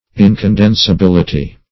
Meaning of incondensibility. incondensibility synonyms, pronunciation, spelling and more from Free Dictionary.
Search Result for " incondensibility" : The Collaborative International Dictionary of English v.0.48: Incondensability \In`con*den`sa*bil"i*ty\, Incondensibility \In`con*den`si*bil"i*ty\, n. The quality or state of being incondensable.